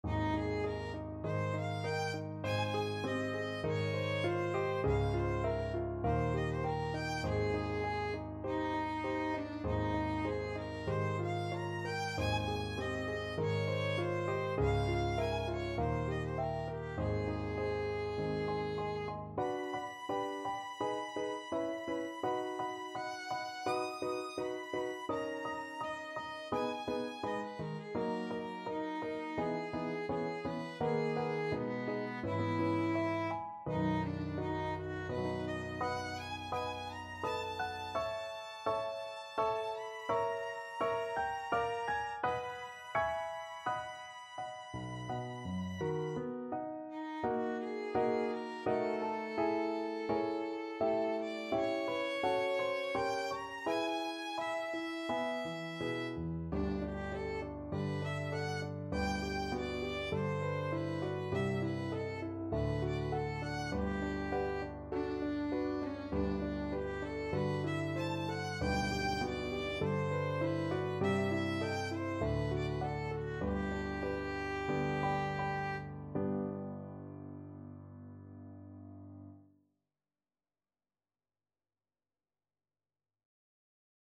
Moderato
4/4 (View more 4/4 Music)
B4-Eb7
Classical (View more Classical Violin Music)